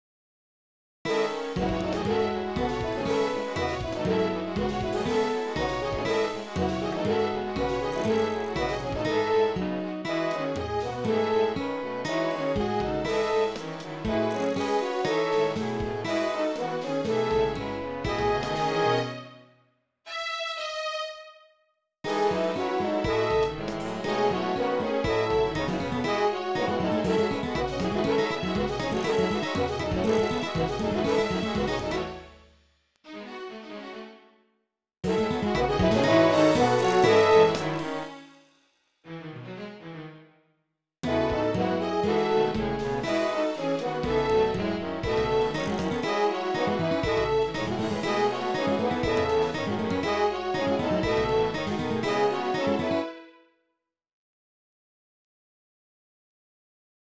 From my Third most recent original musical composition Symphony; Duisburg Somer.